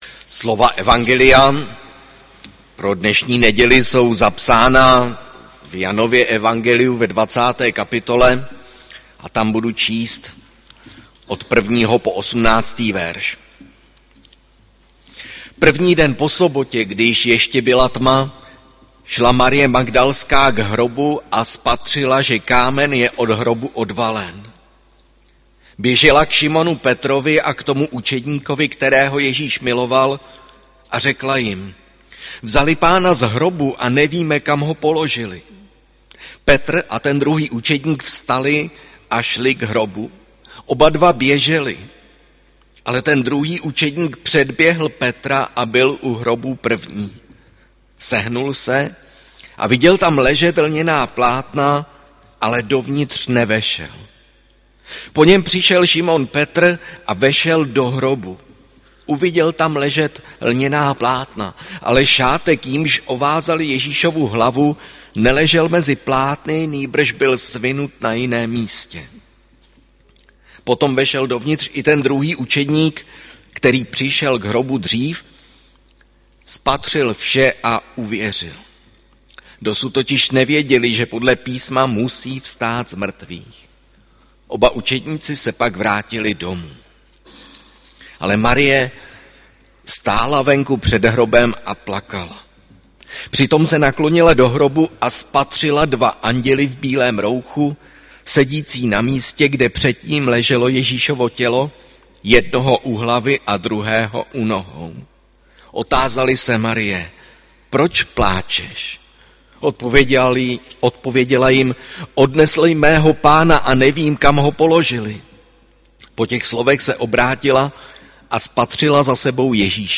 audio kázání